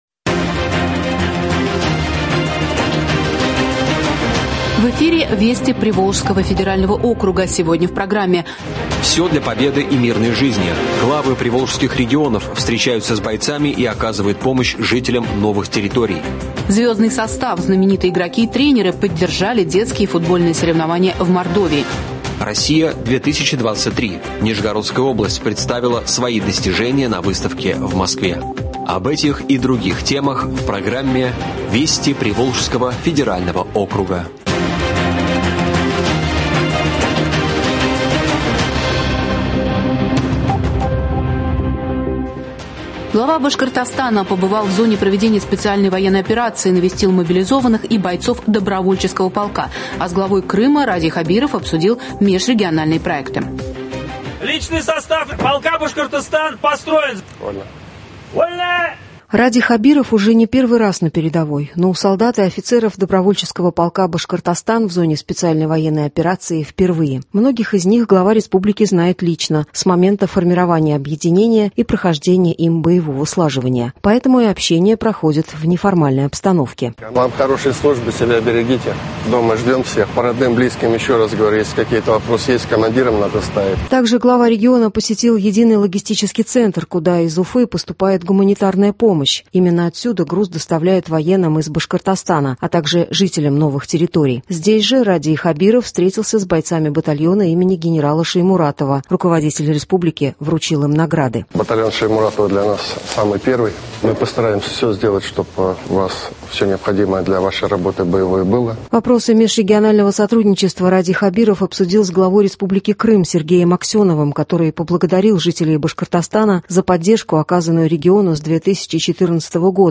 Радиообзор событий недели в регионах ПВО.